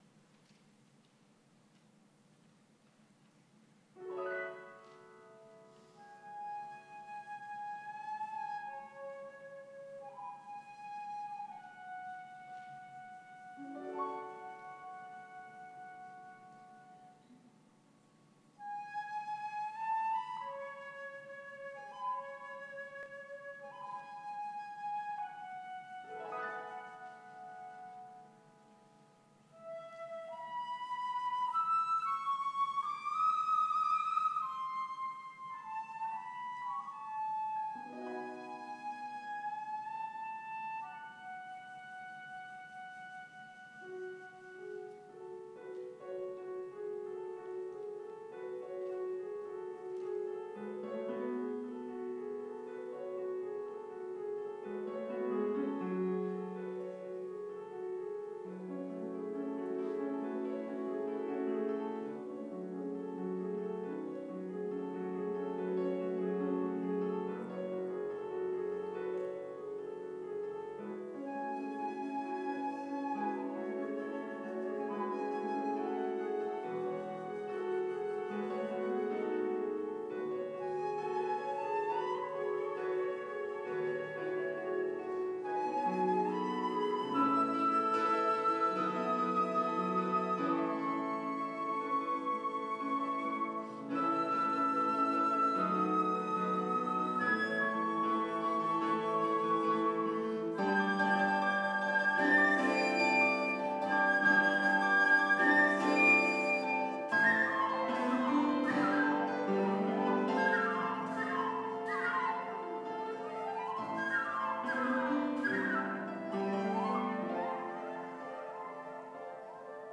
On Easter Sunday – as I have done for the past five years - I shall be performing Bach’s great Toccata and Fugue in D Minor, the Toccata as a prelude and the Fugue as a Postlude. I included this in the recent Classic Concert